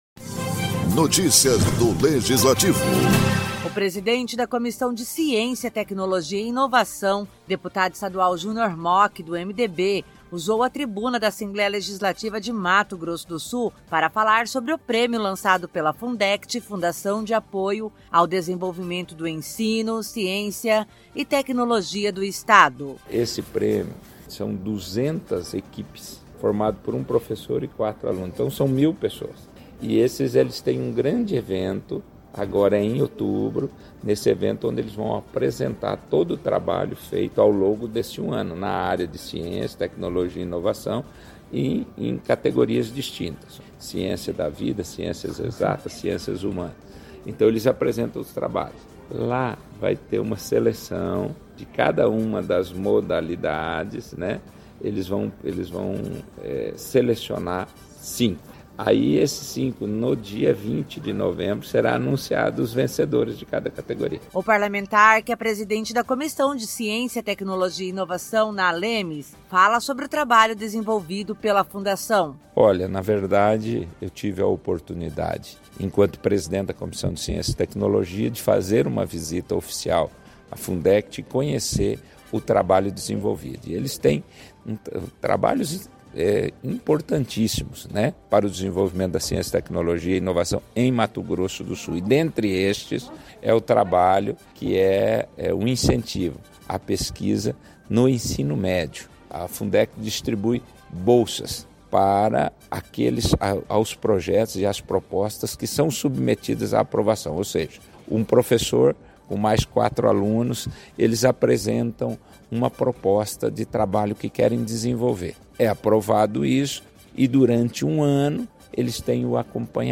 ALEMS realiza no dia 20 de novembro entrega do Prêmio Fundect Pesquisador 2023Lead - Na tribuna, o deputado estadual Junior Mochi, do MDB, falou sobre o prêmio lançado pela Fundação de Apoio ao Desenvolvimento do Ensino, Ciência e Tecnologia do estado de Mato Grosso do Sul (Fundect).